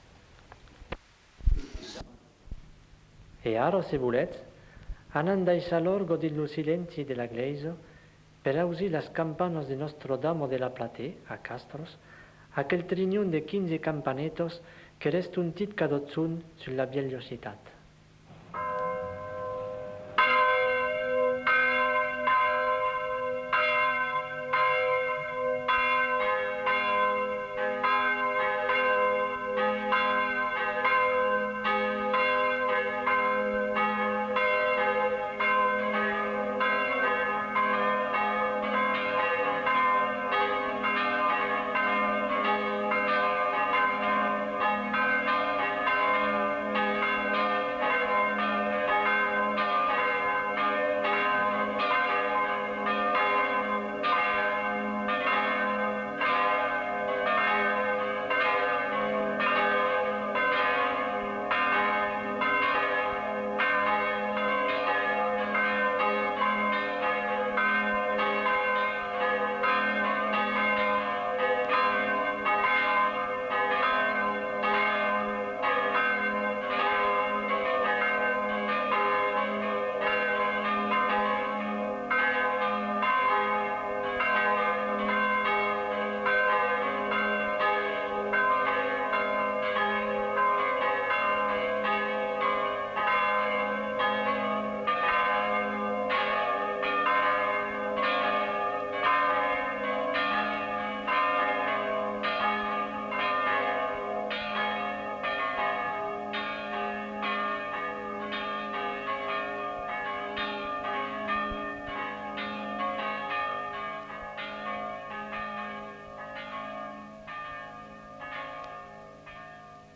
Genre : morceau instrumental
Descripteurs : sonnerie de cloche
Instrument de musique : cloche d'église